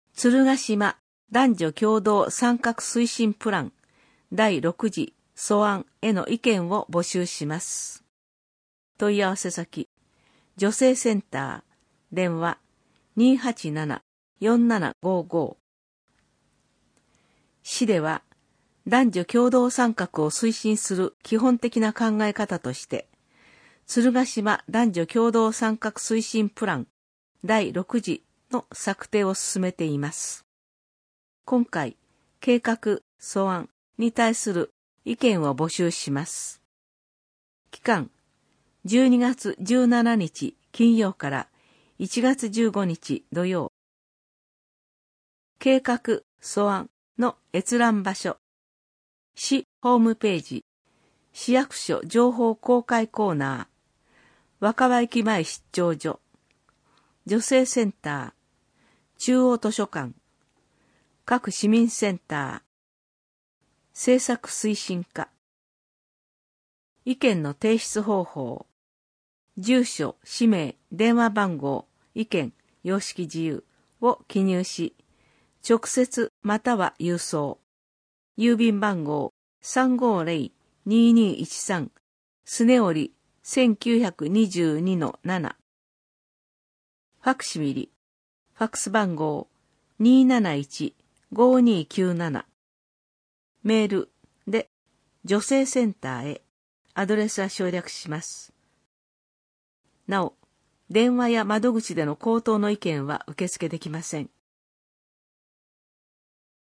声の広報つるがしまは、「鶴ヶ島音訳ボランティアサークルせせらぎ」の皆さんが「広報つるがしま」の内容を音訳し、「デイジー鶴ヶ島」の皆さんがデイジー版CDを製作して、目の不自由な方々へ配布をしています。